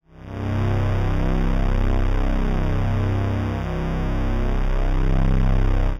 C1_trance_pad_2.wav